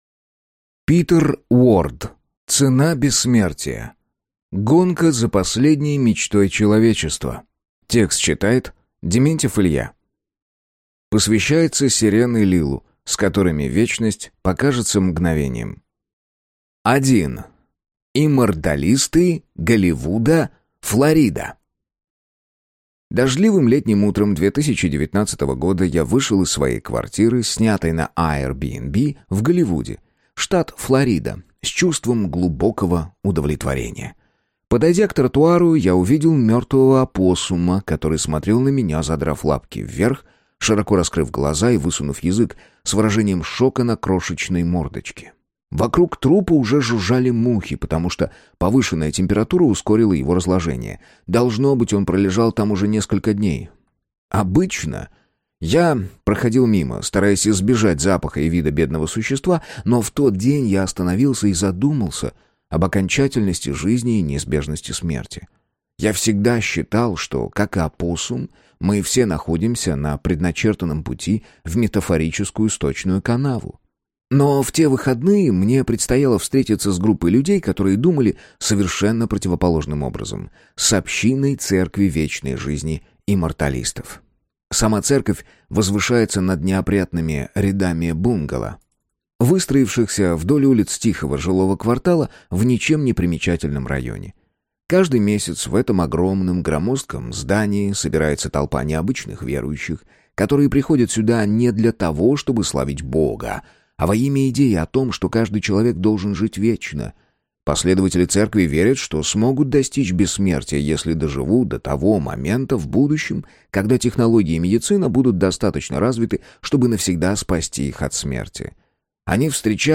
Аудиокнига Цена бессмертия. Гонка за последней мечтой человечества | Библиотека аудиокниг